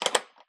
Item Lock (1).wav